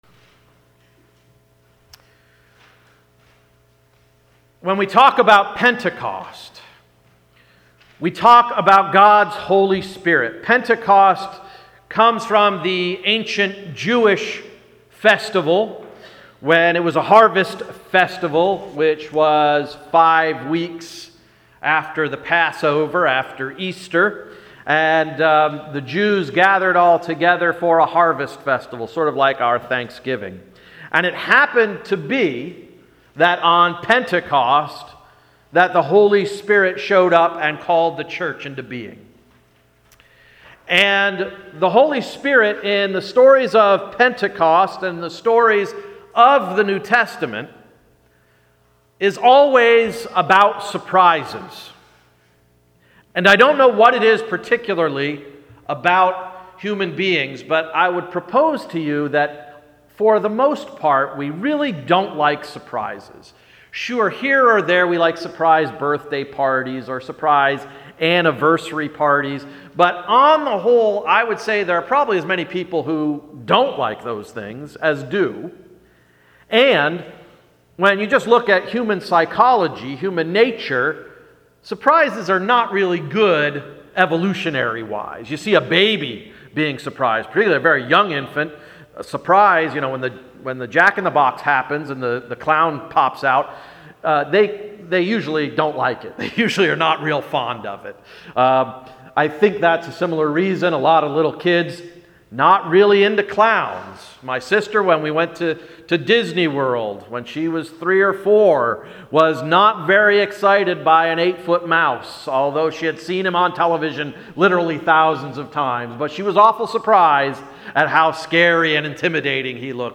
Sermon of May 24, 2015–“Consumed”